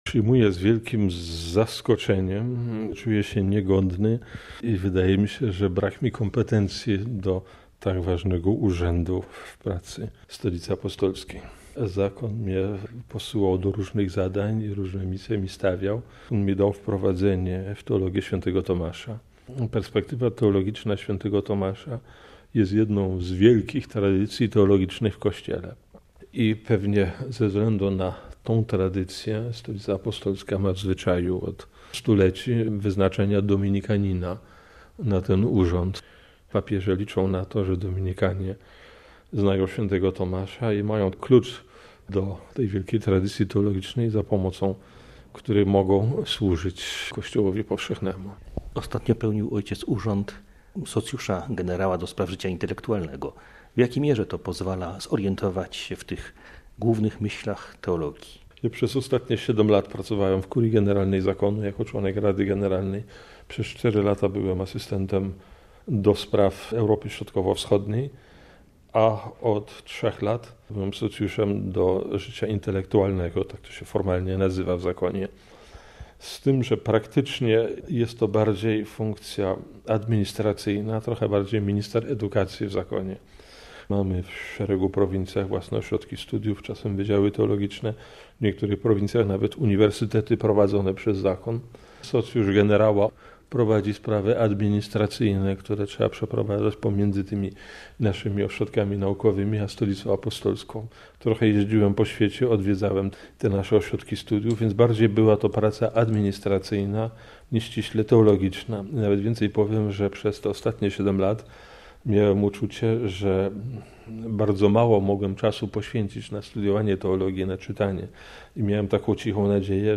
Słuchaj wypowiedzi o. W. Giertycha OP: RealAudio